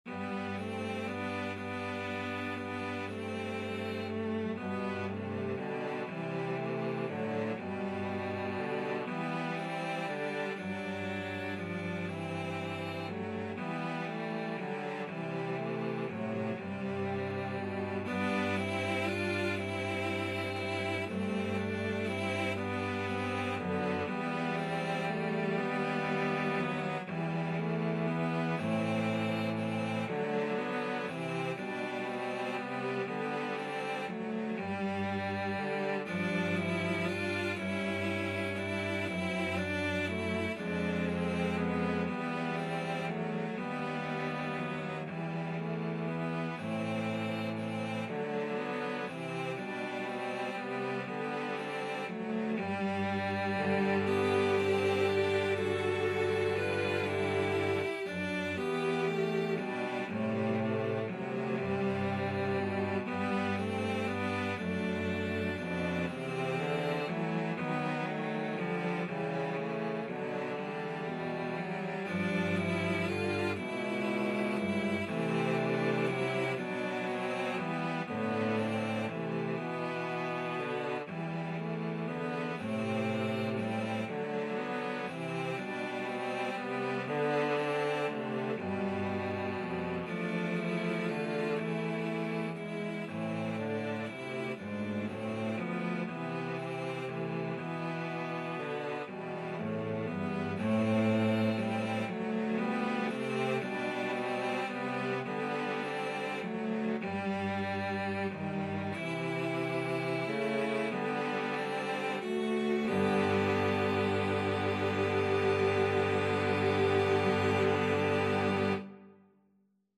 Cello 1Cello 2Cello 3
=120 Andante tranquillo
9/4 (View more 9/4 Music)
Classical (View more Classical Cello Trio Music)